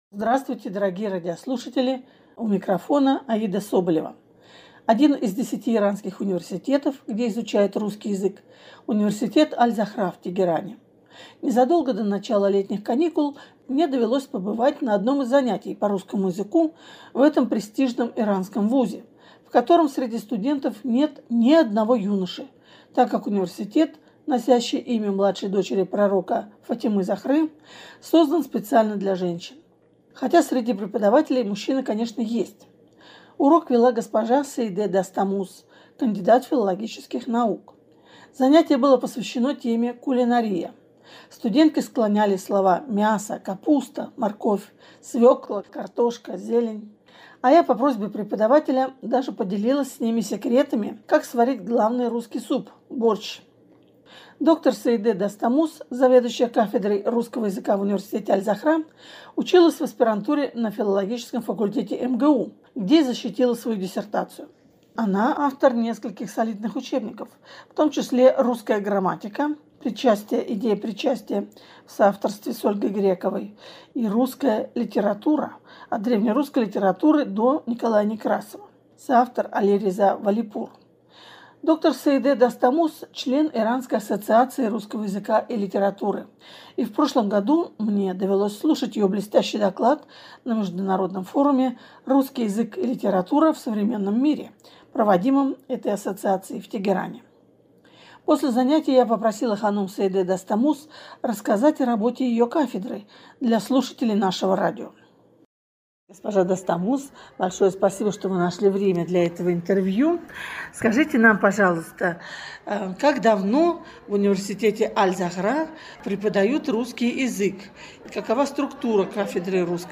Незадолго до начала летних каникул мне довелось побывать на занятии по русскому языку в этом престижном иранском вузе, в котором среди студентов нет ни одного юноши, так как университет, носящий имя младшей дочери Пророка Фатимы-Захры, создан специально для женщин.